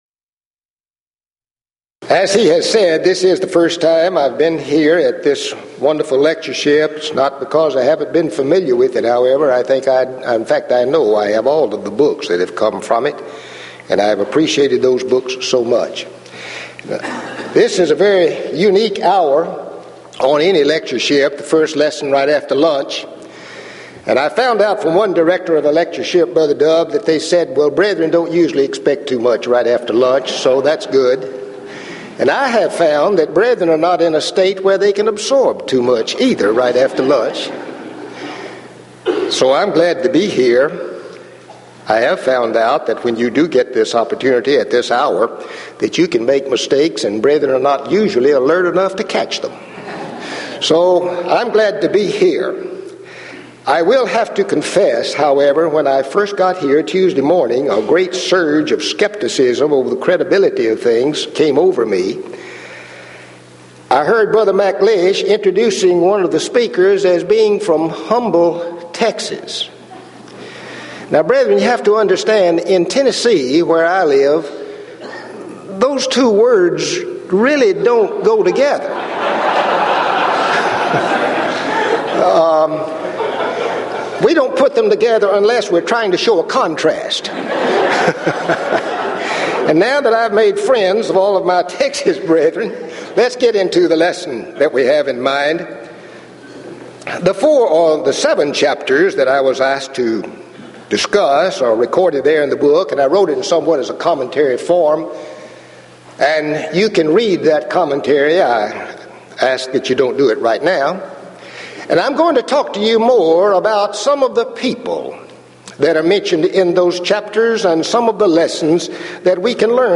Event: 1993 Denton Lectures Theme/Title: Studies In I & II Kings, I & II Chronicles